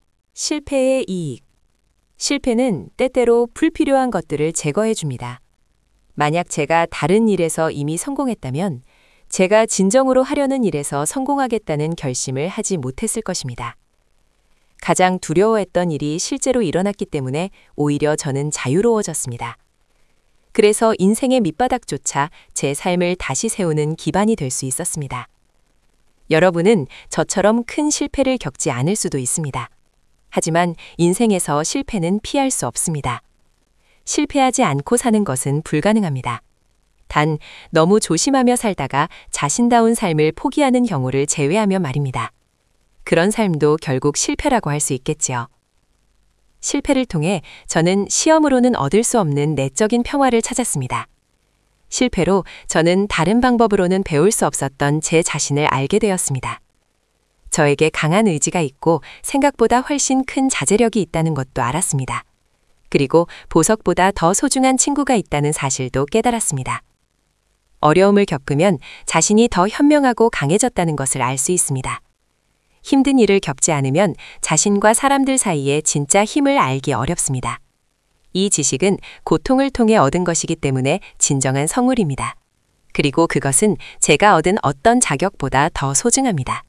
―課題文音声（発音参考用）―